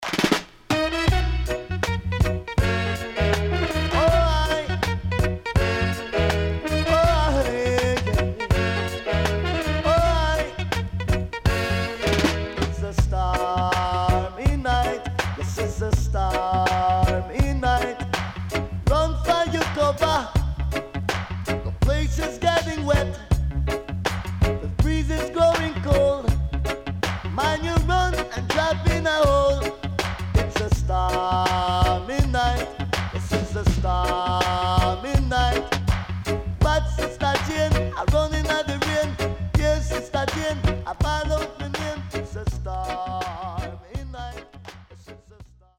HOME > LP [DANCEHALL]
SIDE A:少しノイズ入りますが良好です。